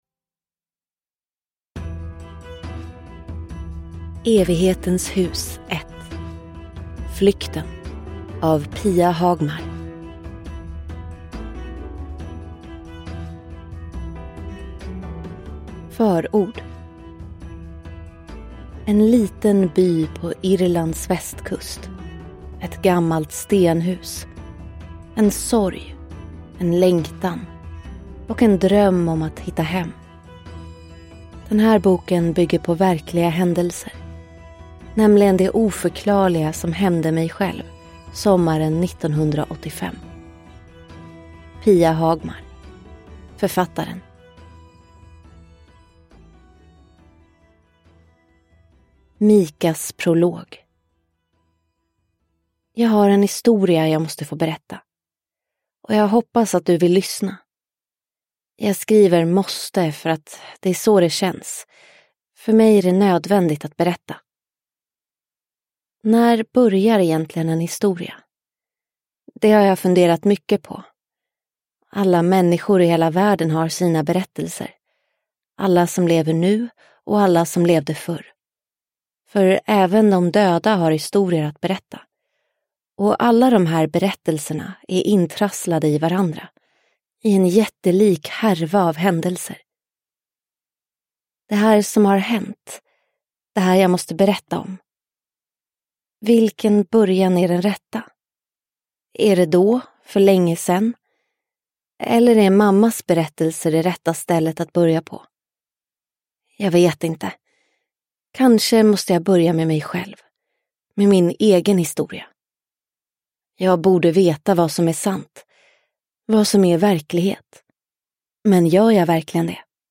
Flykten – Ljudbok – Laddas ner